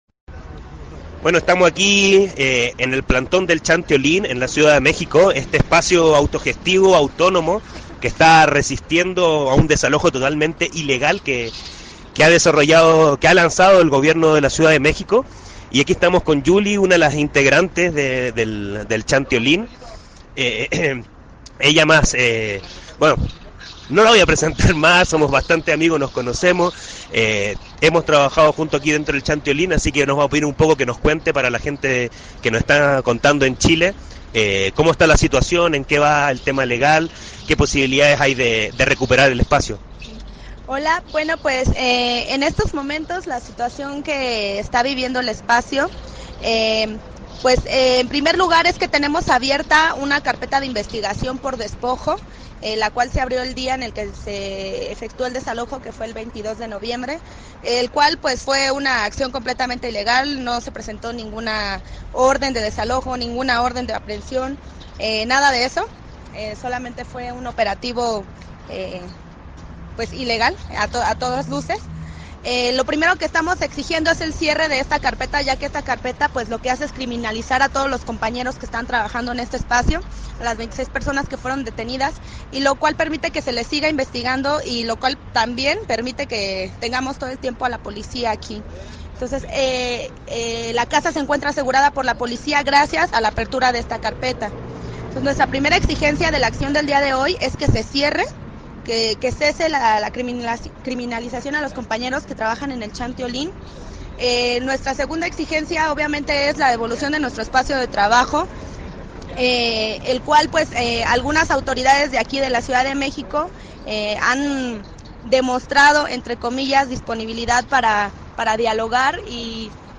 Despacho completo
mientras se desarrolla en plantón en México.